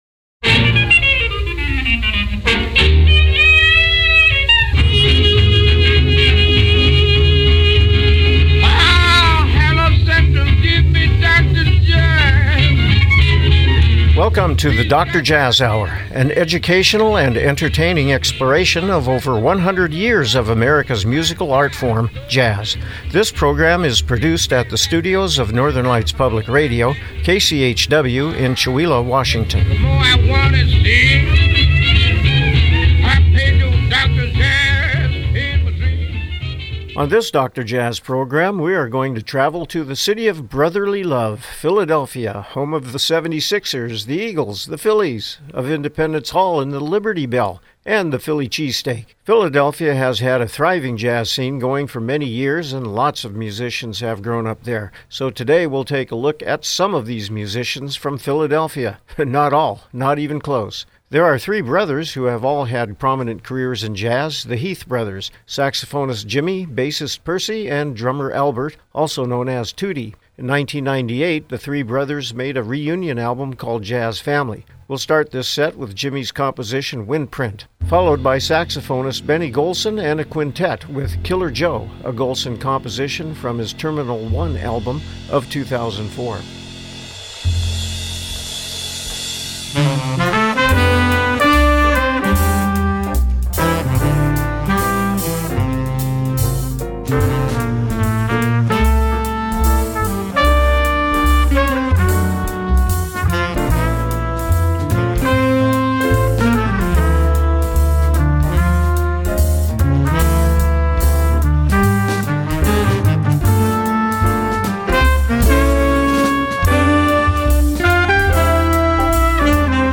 Program Type: Music Speakers